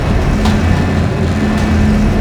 DockingInProgress.wav